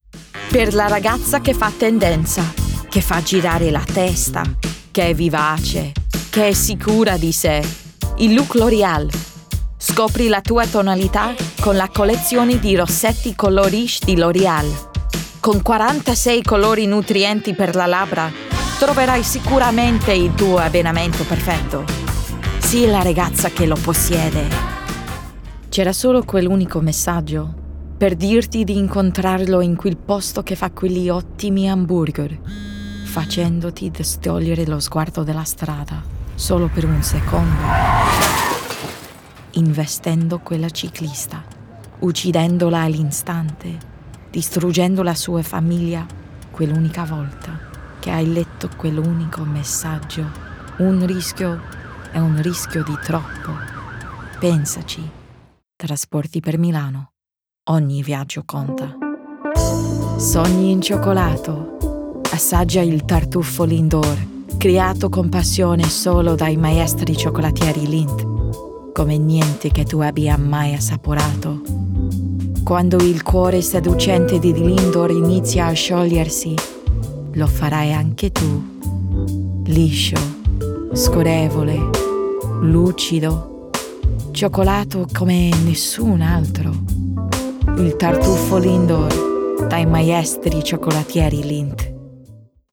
Commercial Reel (Italian)
Commercial, Bright, Upbeat, Italian